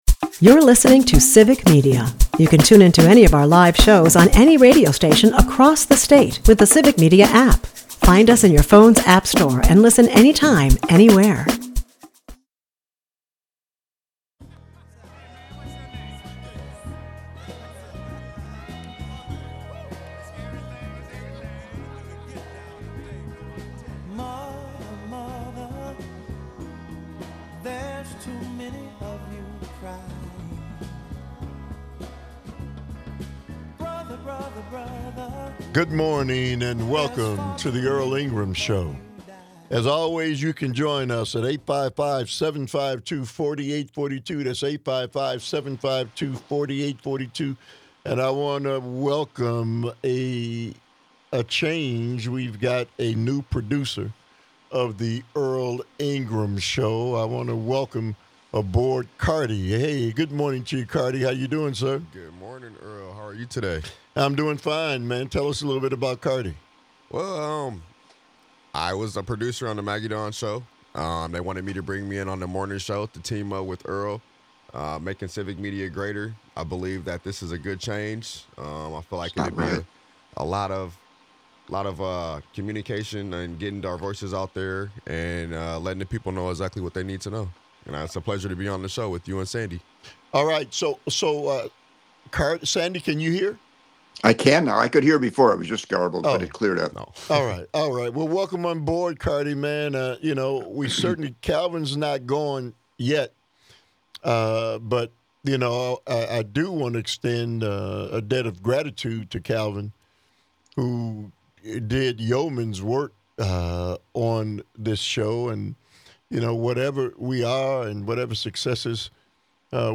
Join the conversation. Broadcasts live 8 - 10am weekdays across Wisconsin.